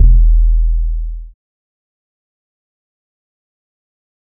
808 (Fuck).wav